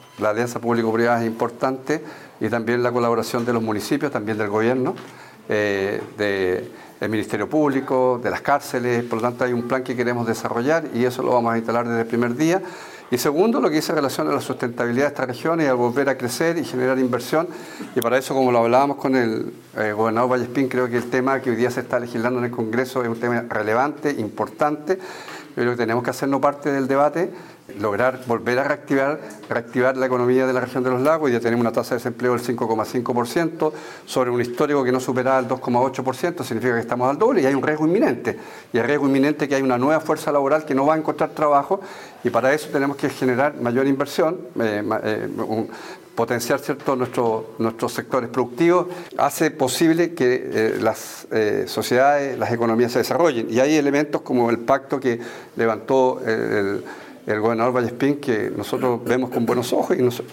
Del mismo modo, Santana expresó que dentro de su planificación de trabajo se encuentra la reactivación económica regional, dado el alza que ha tenido durante los últimos años, por lo que buscará trabajar mediante múltiples alianzas público-privadas, junto a los 30 municipios.